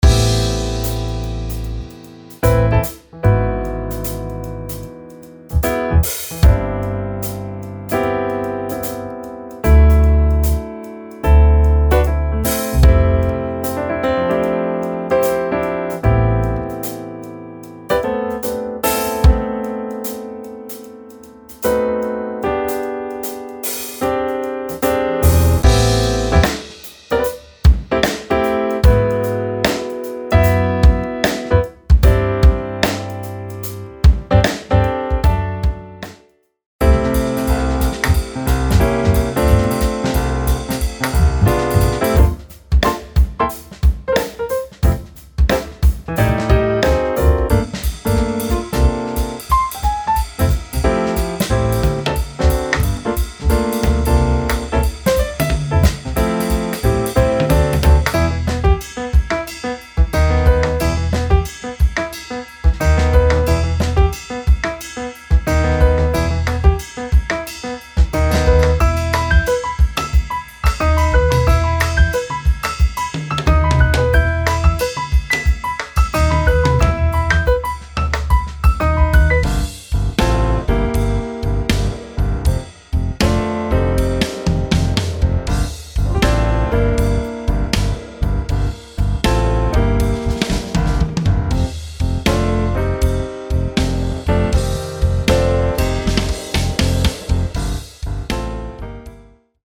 Blues – 160bpm – C
Contemporary Smooth Jazz – 90bpm Am